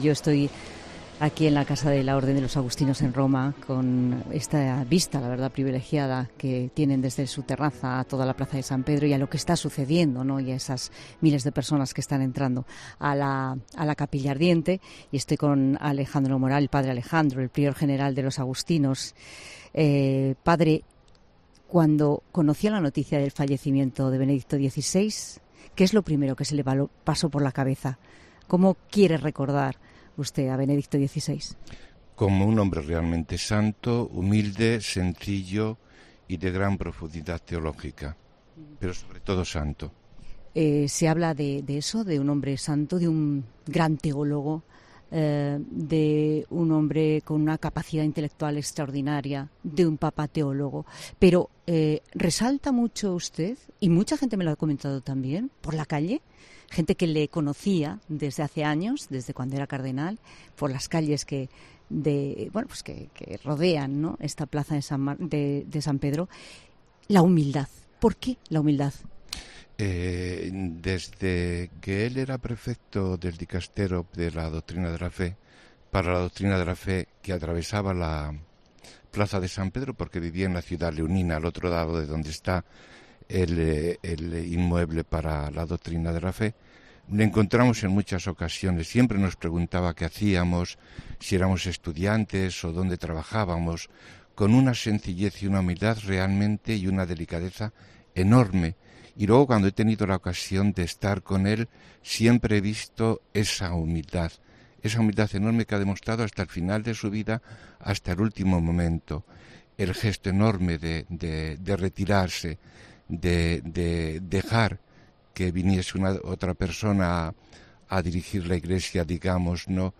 En concreto, está en la terraza de la casa de la Orden de los Agustinos en Roma, desde donde pueden disfrutarse unas vistas privilegiadas del que es ahora mismo el epicentro del Vaticano.